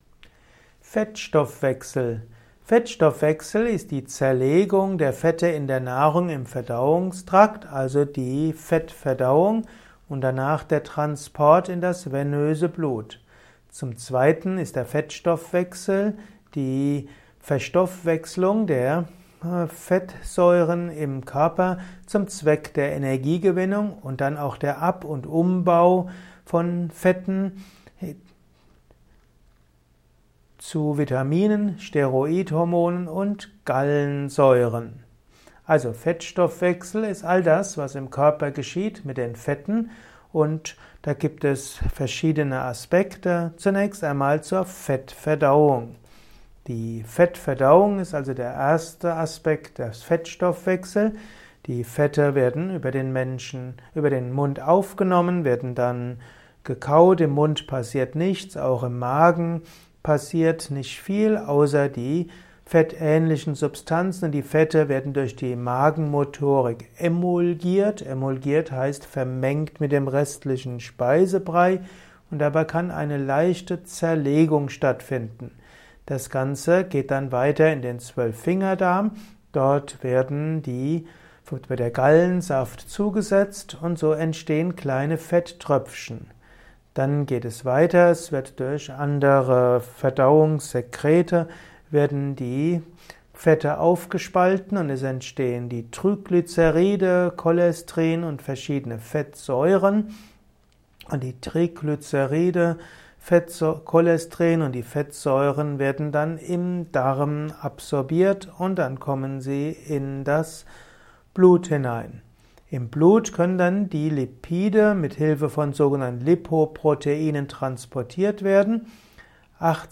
Ein Kurzvortrag über den Fettstoffwechsel